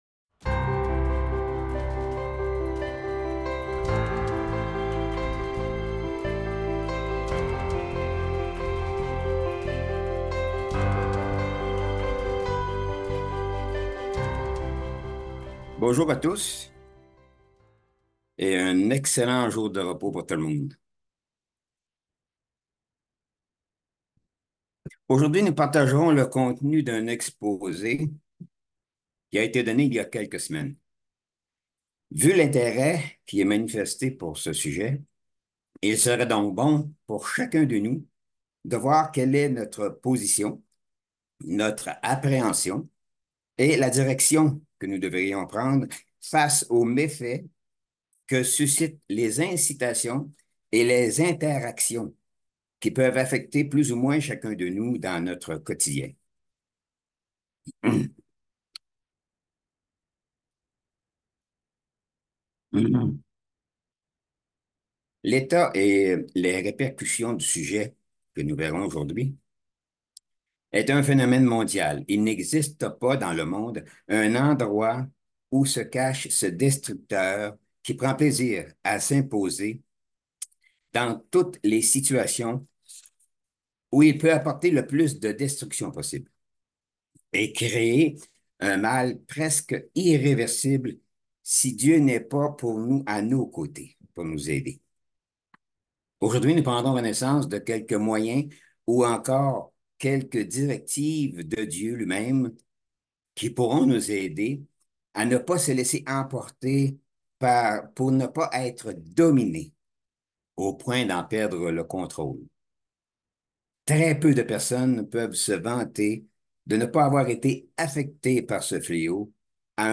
Given in Bordeaux